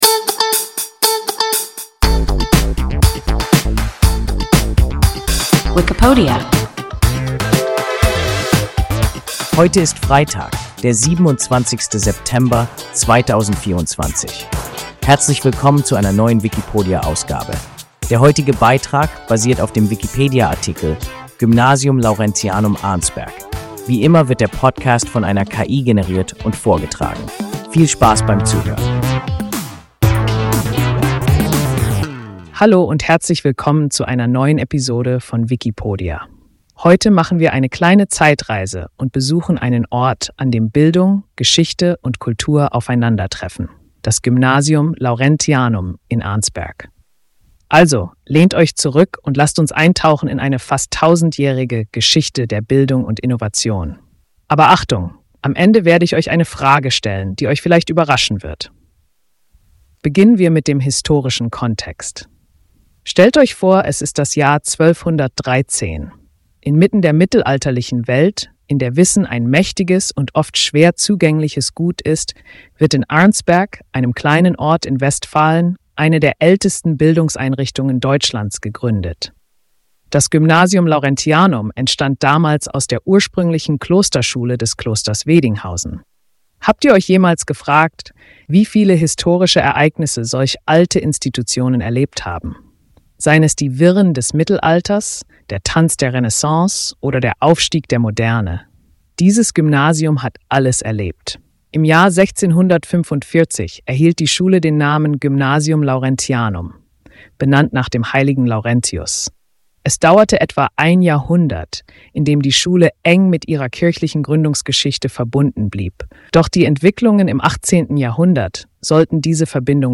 Gymnasium Laurentianum Arnsberg – WIKIPODIA – ein KI Podcast